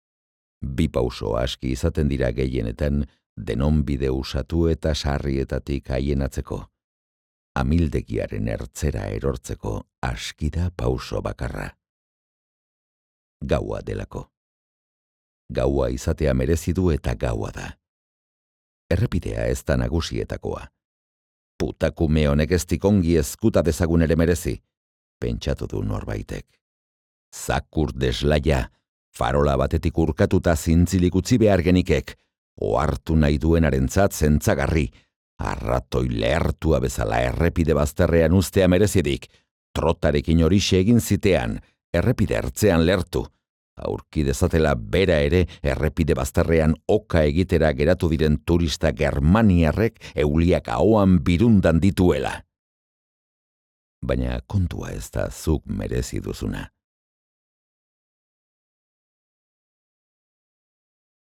Euskal idazleen audioliburuak paratu ditu sarean eitb-k